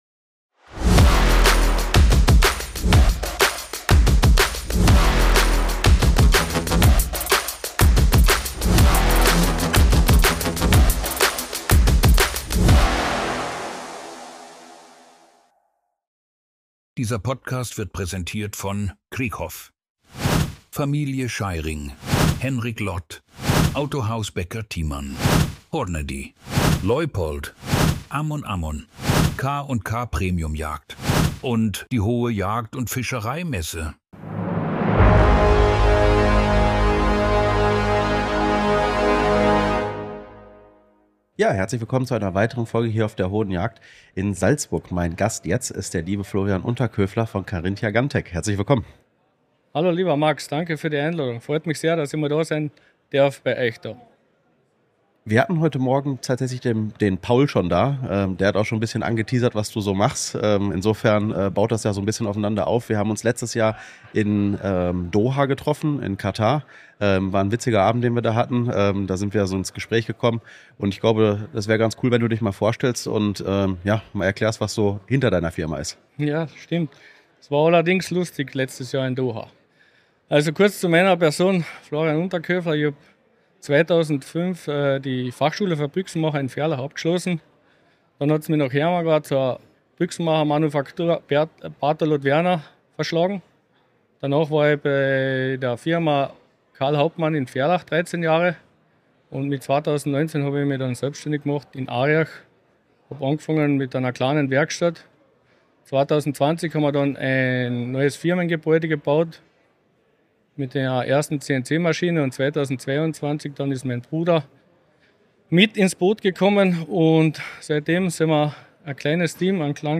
der Messe Hohe Jagd in Salzburg.